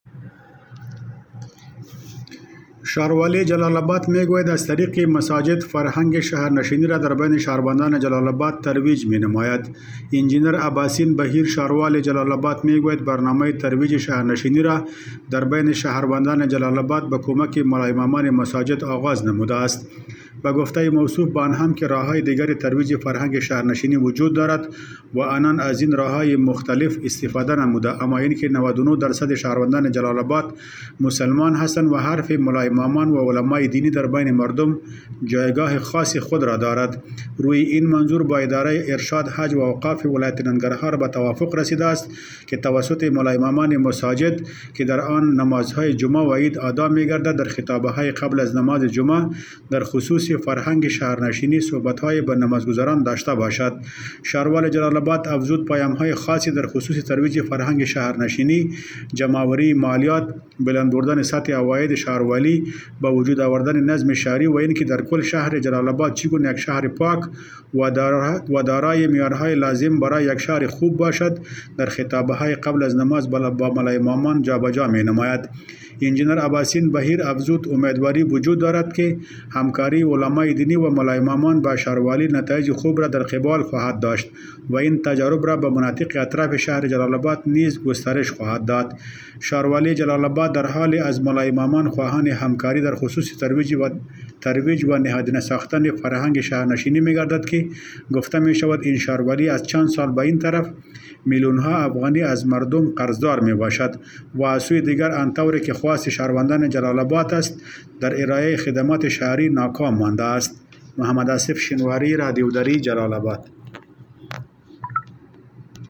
جزئیات بیشتر این خبر در گزارش